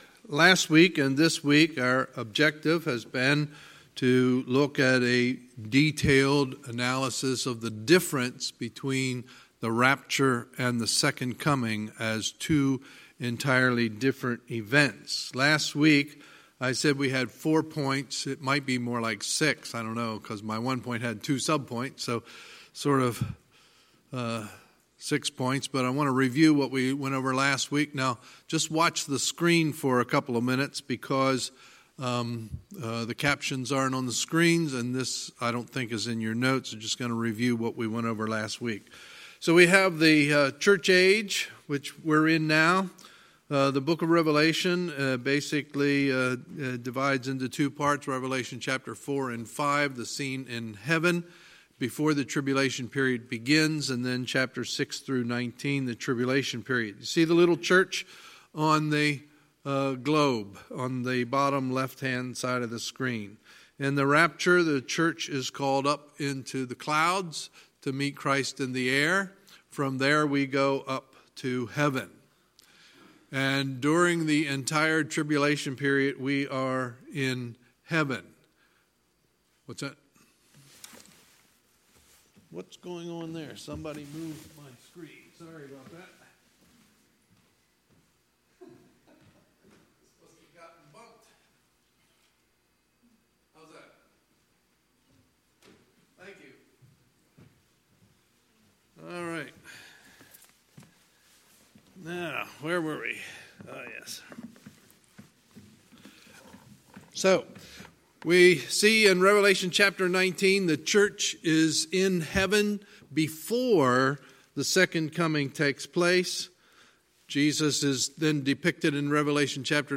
Sunday, June 2, 2019 – Sunday Evening Service